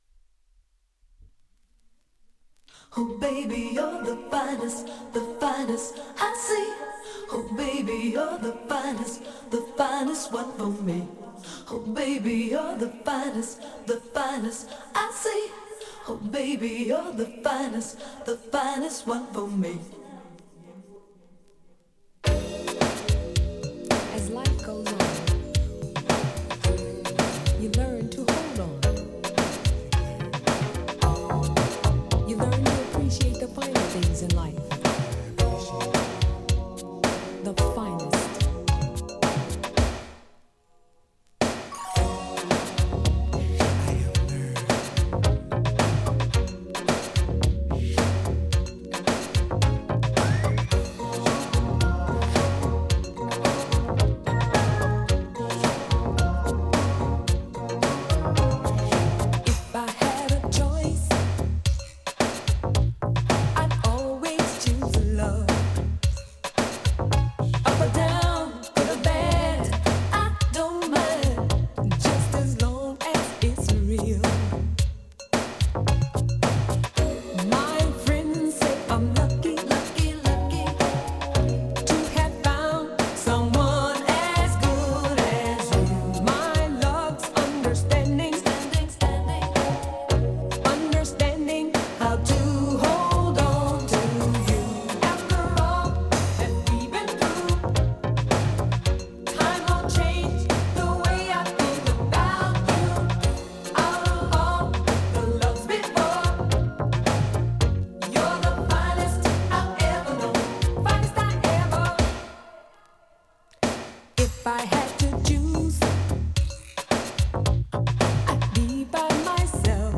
> DISCO・FUNK・SOUL・JAZZ
当時は、ミラクル・ファンクという言い方もされていた？
洒落たミッド・ダンサー！！
EXTENDED VERSION